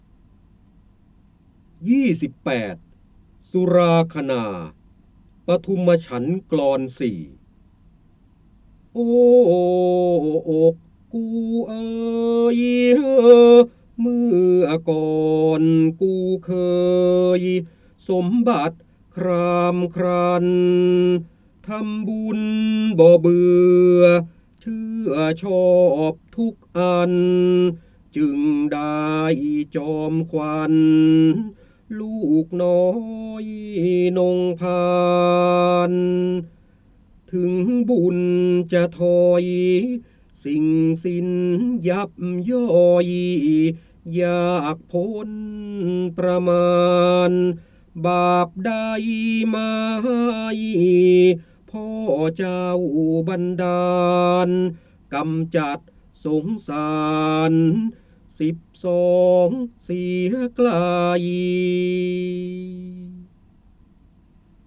เสียงบรรยายจากหนังสือ จินดามณี (พระโหราธิบดี) สุราคณา ปทุมฉันท์กลอน ๔
คำสำคัญ : พระเจ้าบรมโกศ, การอ่านออกเสียง, พระโหราธิบดี, ร้อยกรอง, ร้อยแก้ว, จินดามณี